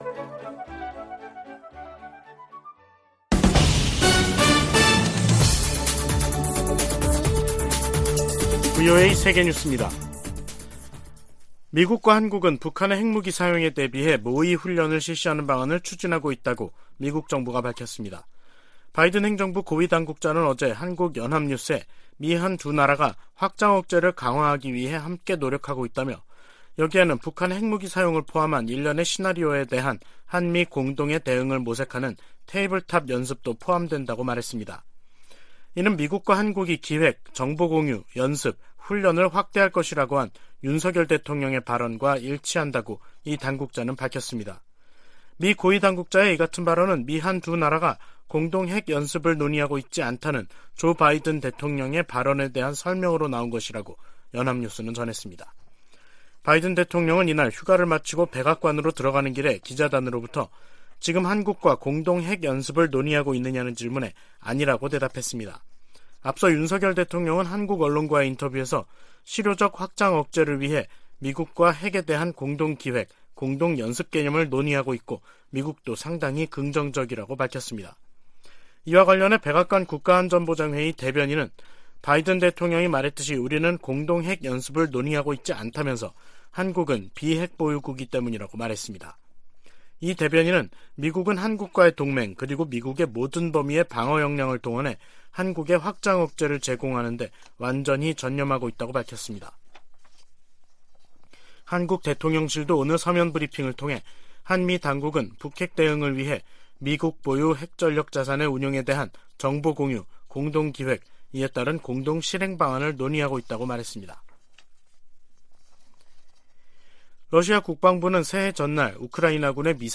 VOA 한국어 간판 뉴스 프로그램 '뉴스 투데이', 2023년 1월 3일 3부 방송입니다. 유럽연합과 영국, 캐나다 등이 북한에 군사적 긴장 고조행위를 중단하고 비핵화 대화에 복귀하라고 촉구했습니다. 조 바이든 미국 대통령에게는 북한 핵 문제를 비롯한 세계적인 핵무기 위험이 새해에도 주요 외교적 도전이 될 것이라고 미국 외교전문지가 지적했습니다.